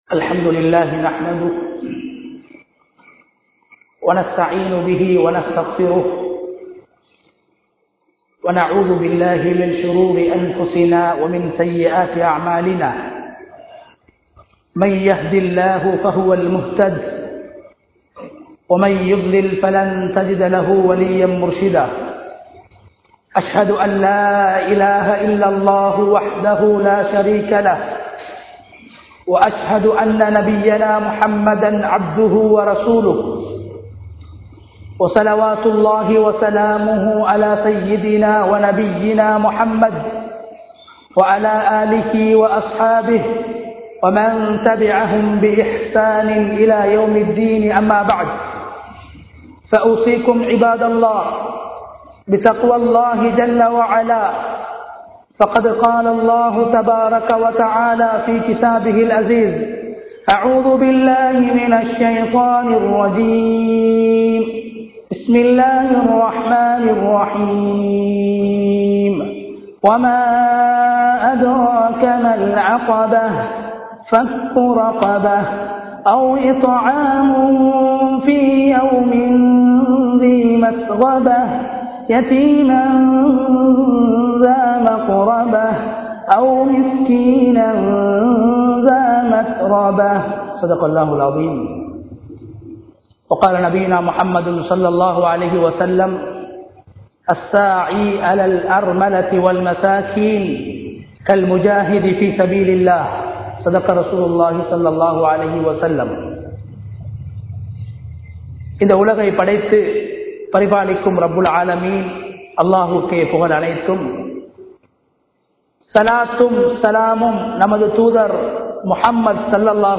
ஏழைகளுக்கு உதவுவோம் | Audio Bayans | All Ceylon Muslim Youth Community | Addalaichenai